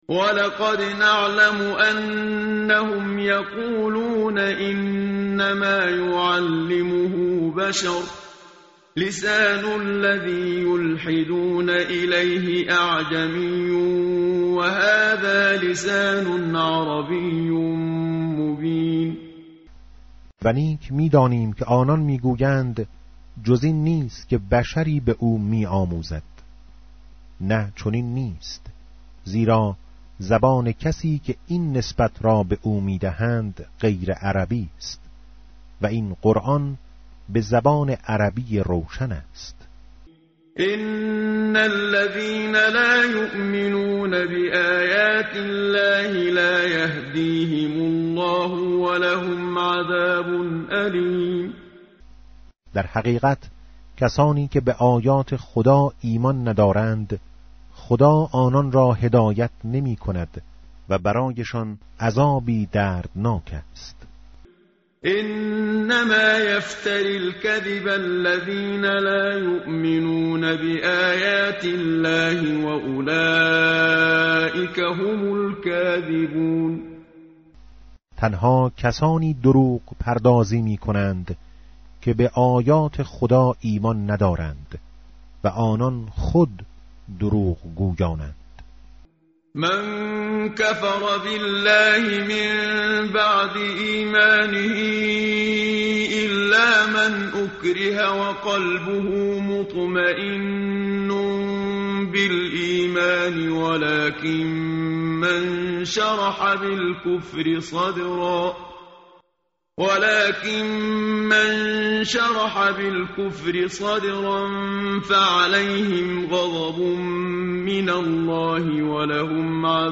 متن قرآن همراه باتلاوت قرآن و ترجمه
tartil_menshavi va tarjome_Page_279.mp3